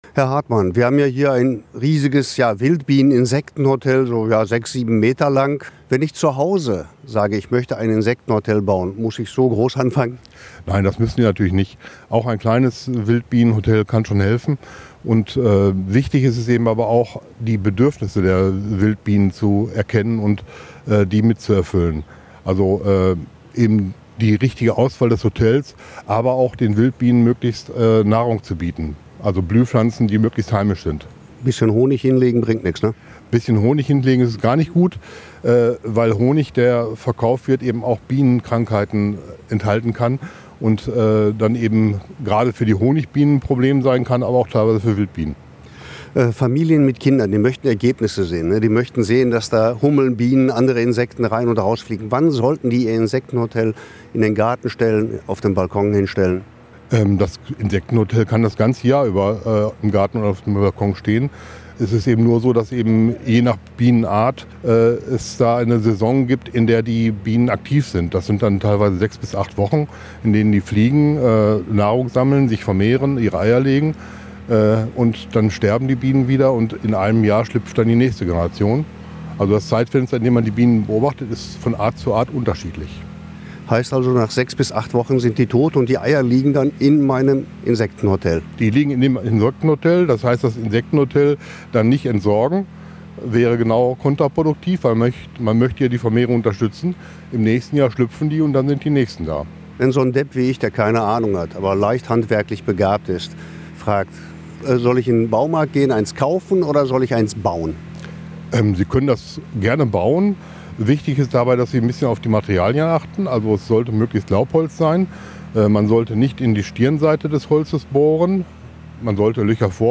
insektenhotel-interview-fuer-online.mp3